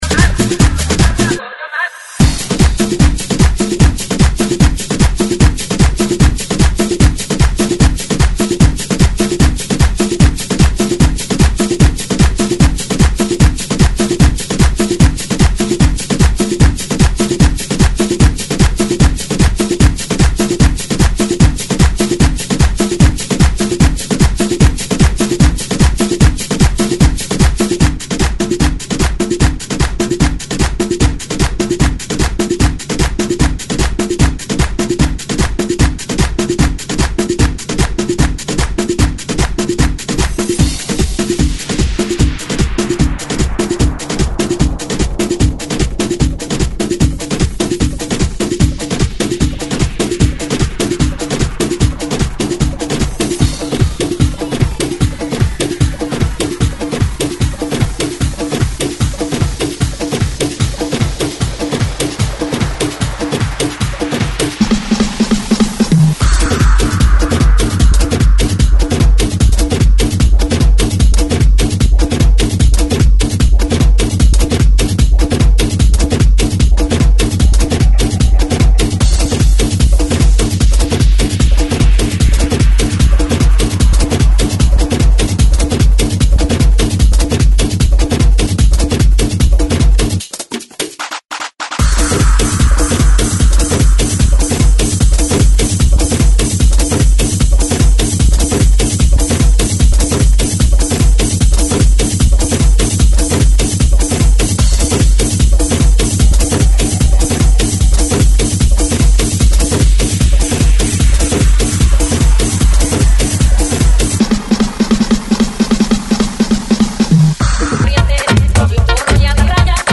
GENERO: LATINO ANTHEM REMIX